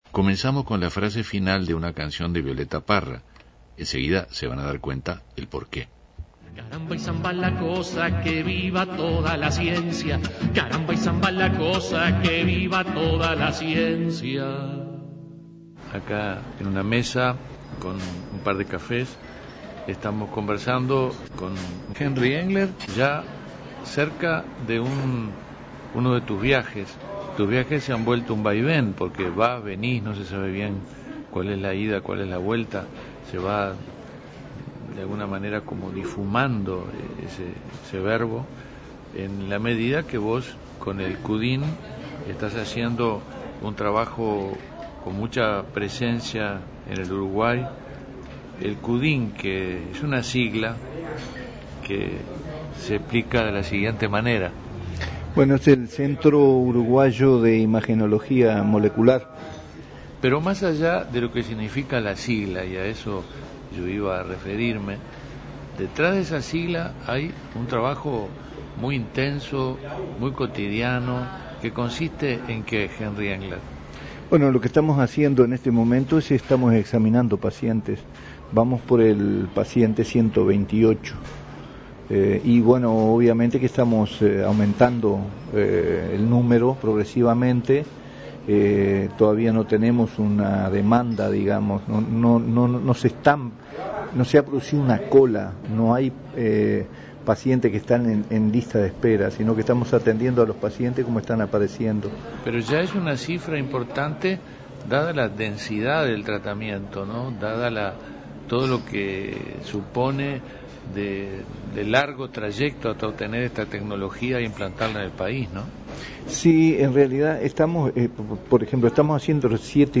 Entrevistas y música con Henry Engler